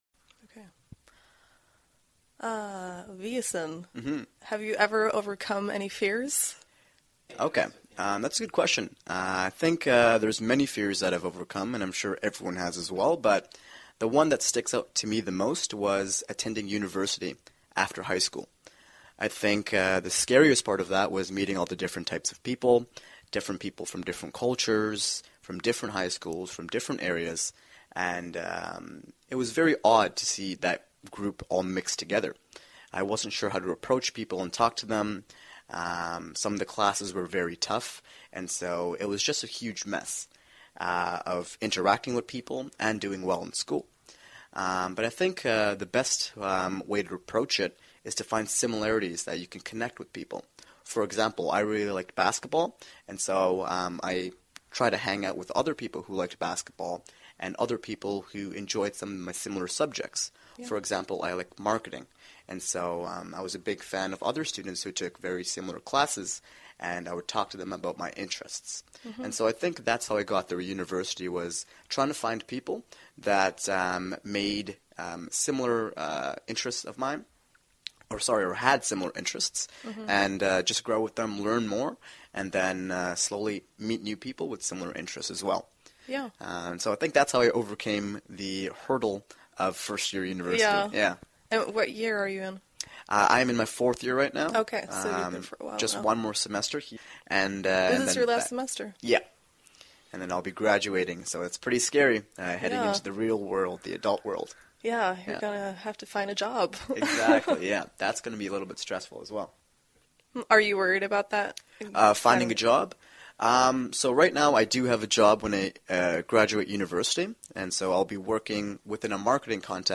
实战口语情景对话 第1373期:Overcoming Fear 克服恐惧(1)